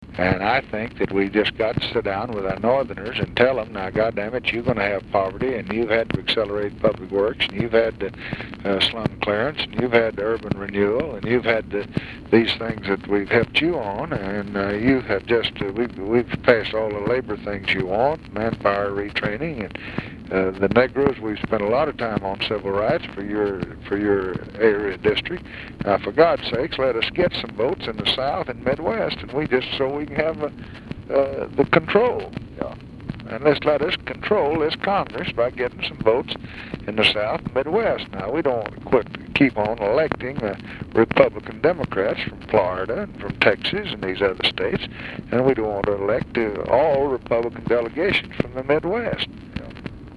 In this brief March 1964 clip, Pres. Johnson and legislative liaison Larry O’Brien discuss the political effects of the farm bill (where the unpopular items dealt with subsidies for cotton and wheat farmers); and in the process provide a clear definition of how congressional logrolling can work.